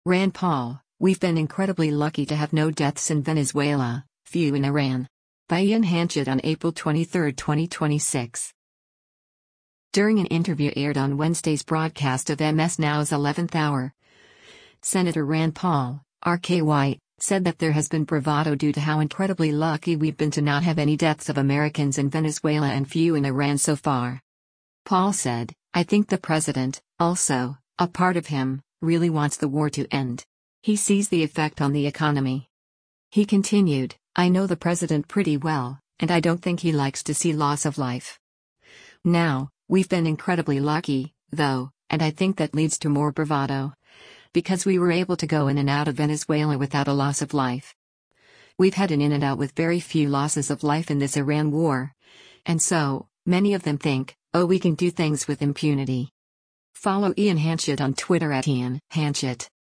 During an interview aired on Wednesday’s broadcast of MS NOW’s “11th Hour,” Sen. Rand Paul (R-KY) said that there has been “bravado” due to how “incredibly lucky” we’ve been to not have any deaths of Americans in Venezuela and few in Iran so far.